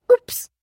На этой странице собраны звуки «упс» — весёлые и искренние реакции на мелкие промахи.
Звук, когда ребенок нечаянно говорит ой